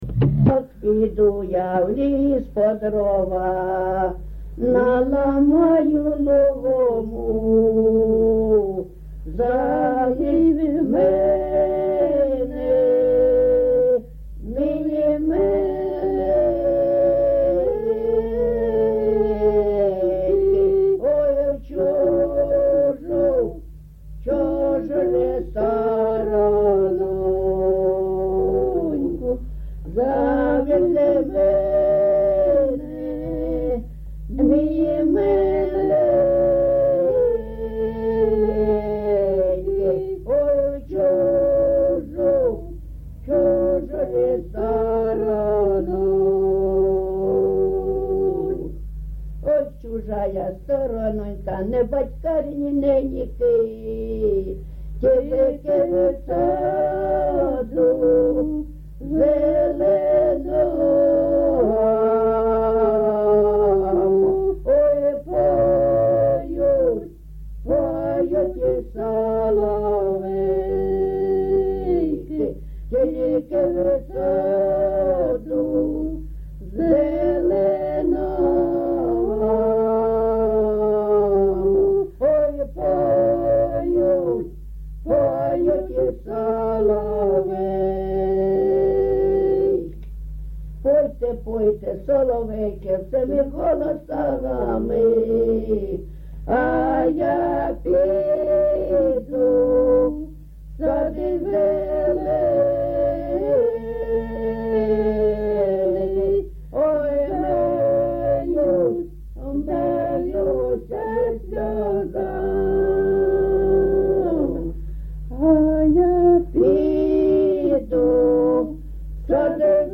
Місце записум. Сіверськ, Артемівський (Бахмутський) район, Донецька обл., Україна, Слобожанщина